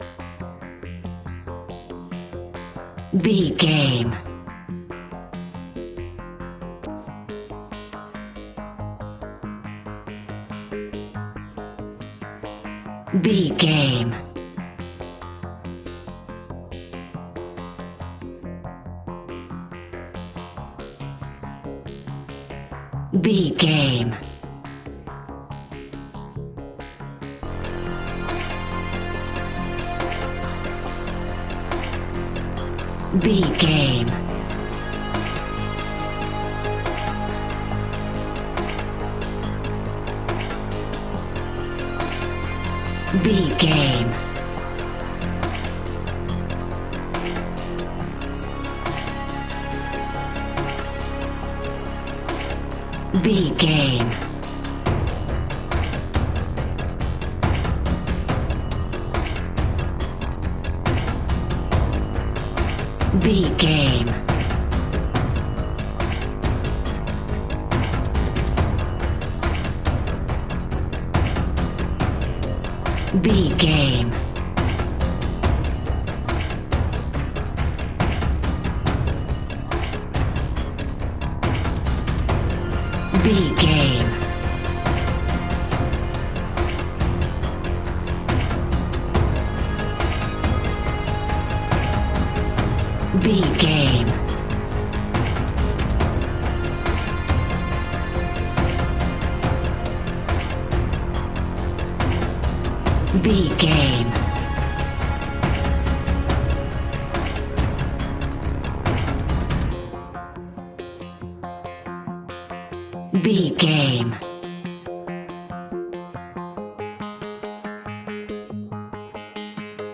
In-crescendo
Aeolian/Minor
ominous
eerie
horror music
Horror Pads
horror piano
Horror Synths